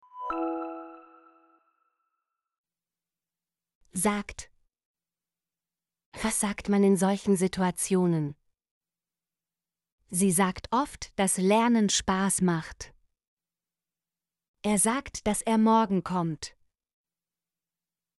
sagt - Example Sentences & Pronunciation, German Frequency List